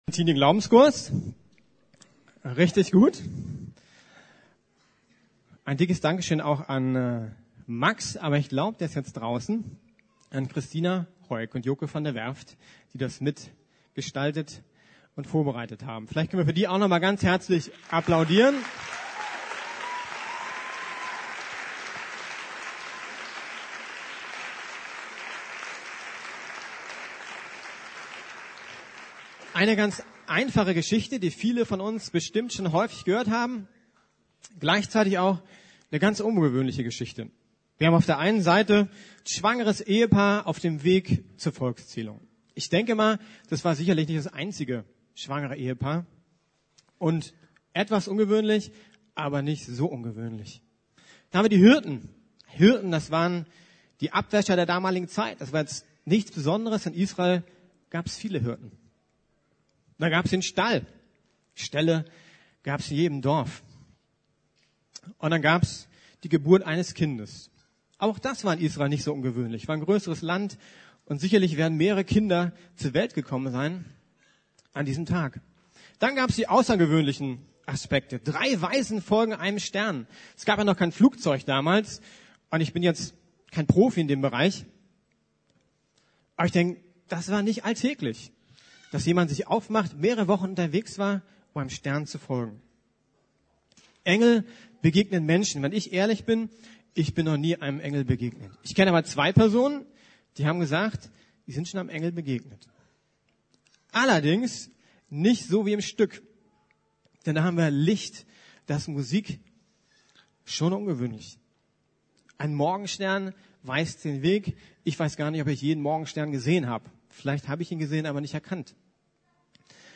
Was macht Weihnachten aus? ~ Predigten der LUKAS GEMEINDE Podcast